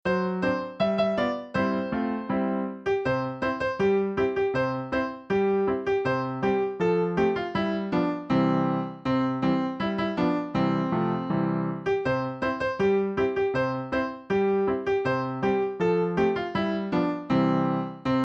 Nursery Rhyme Lyrics